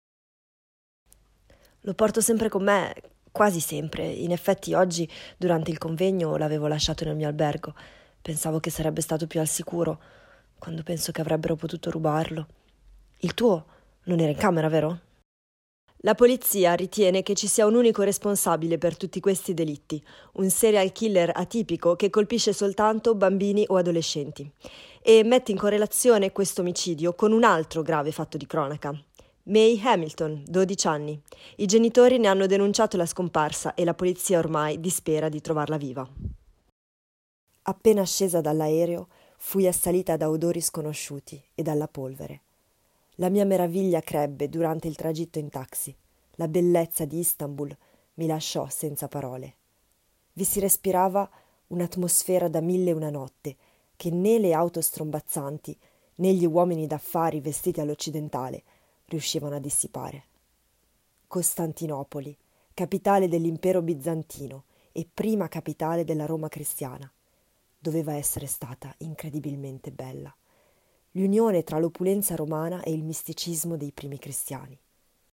Voice reel Italian
Voice demo reel Italian